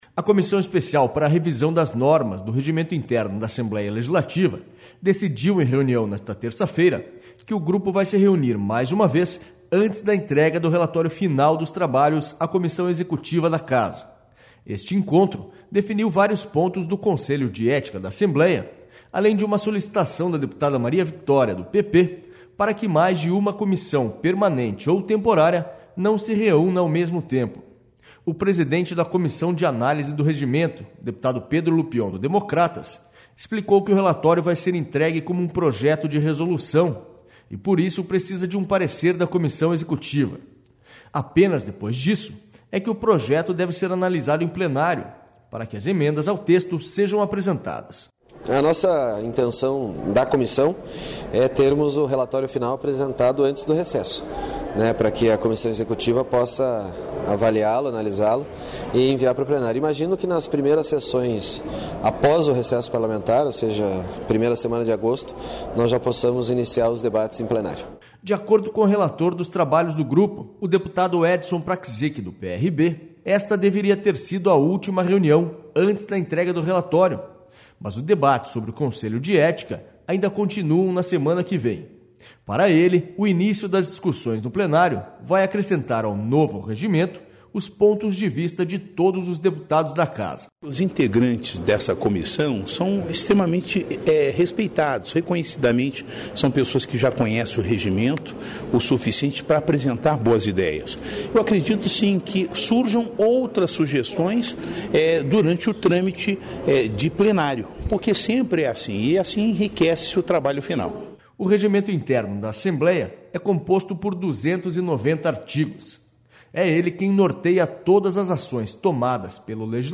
SONORA PEDRO LUPION
SONORA EDSON PRACZYK